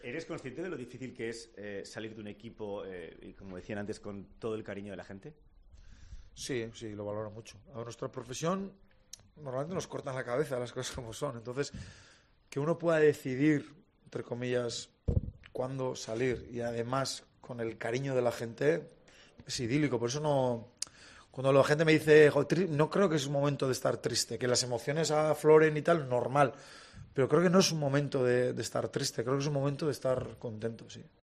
AUDIO: Jagoba Arrasate da su última rueda de prensa en la previa de un partido en el Club Atlético Osasuna